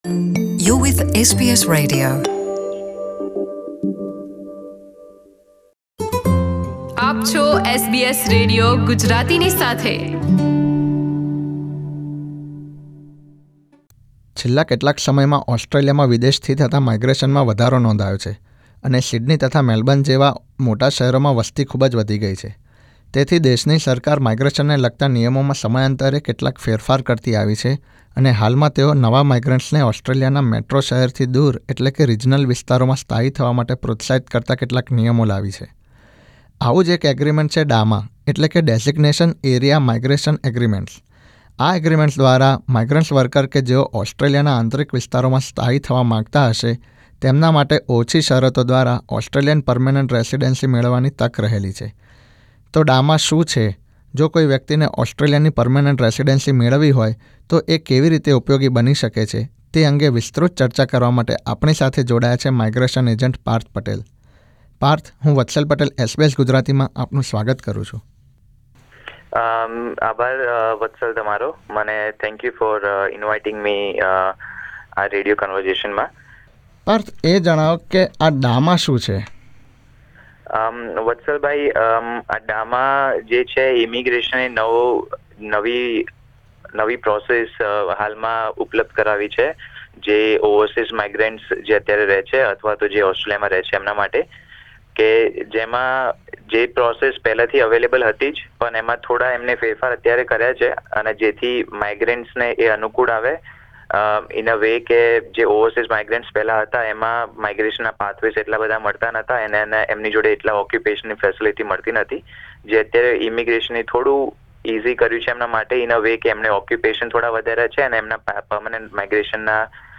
SBS Gujarati ની DAMA વિશે વિશેષ વાતચીત. વિક્ટોરિયાની વર્નમ્બુલ કાઉન્સિલ તથા નોધર્ન ટેરીટરી સરકારે કોમનવેલ્થ સાથે DAMA અંતર્ગત નવા વિસા કરાર કર્યા છે. જેના દ્વારા ઓસ્ટ્રેલિયાના PR મેળવવાનો એક નવો વિકલ્પ ખૂલ્યો છે.